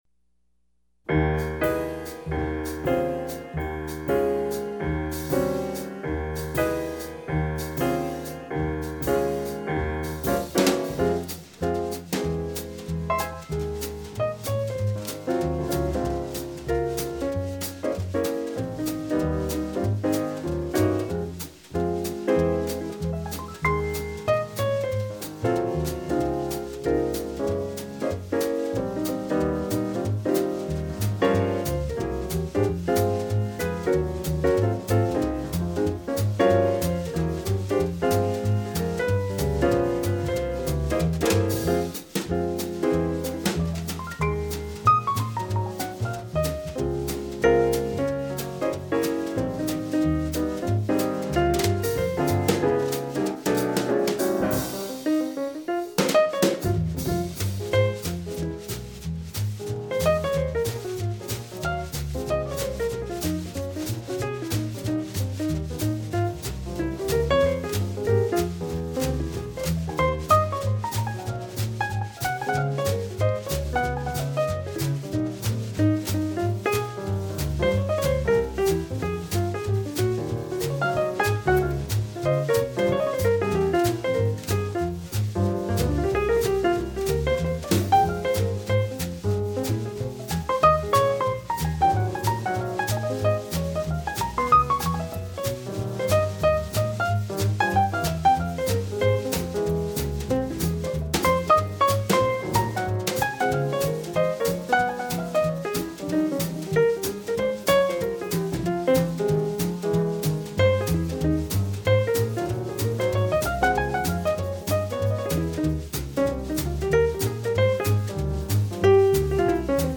plays host to all five players with a hard-bop groove
Piano
Bass
Alto Saxaphone
Trumpet
Drums